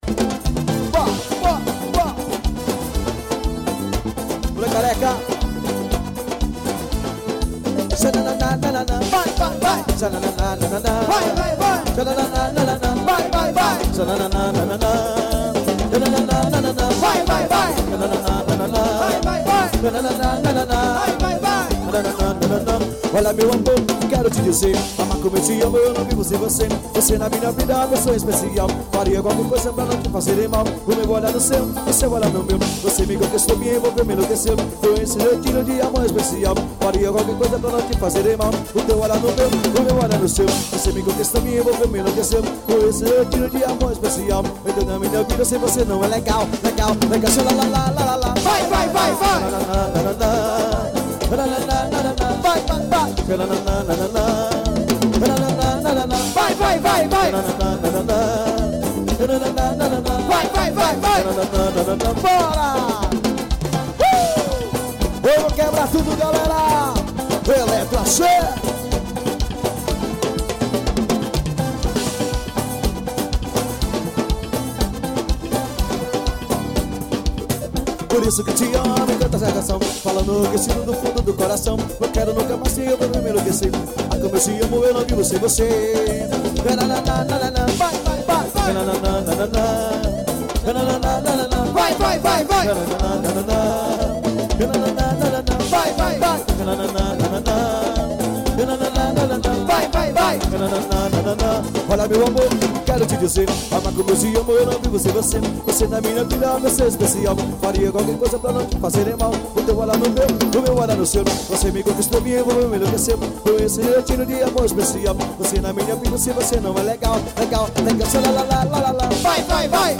EstiloAxé